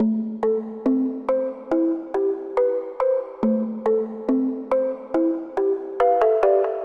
Tag: 140 bpm Chill Out Loops Synth Loops 1.15 MB wav Key : A